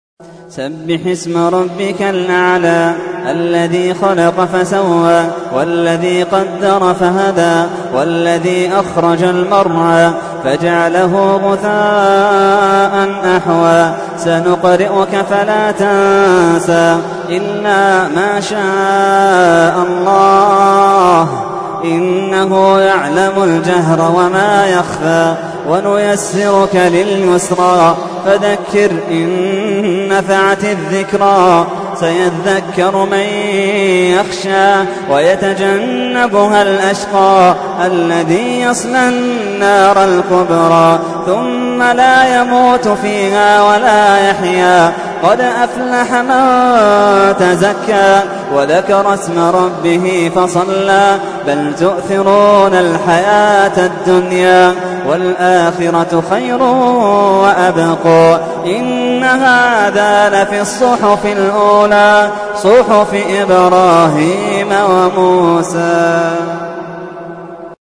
تحميل : 87. سورة الأعلى / القارئ محمد اللحيدان / القرآن الكريم / موقع يا حسين